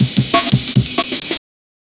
It's a classic breakbeat used in drum and bass, hip-hop, and electronic music.